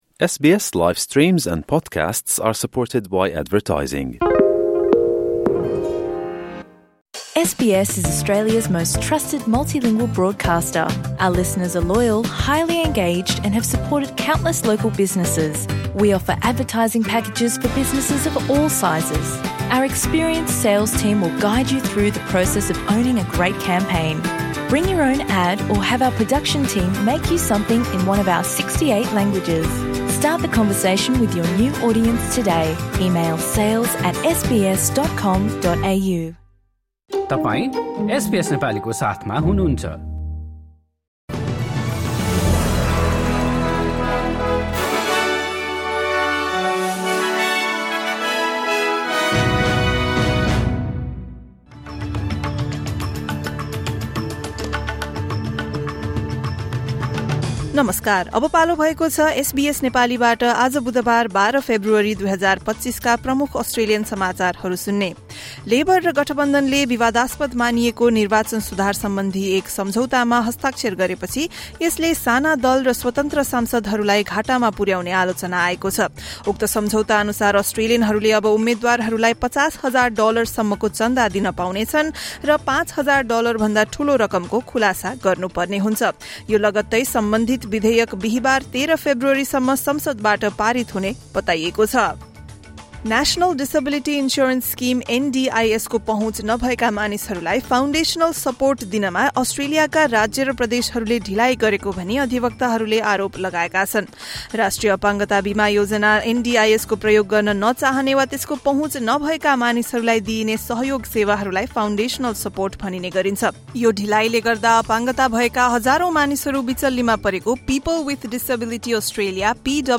SBS Nepali Australian News Headlines: Wednesday, 12 February 2025